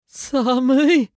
青年ボイス～シチュエーションボイス～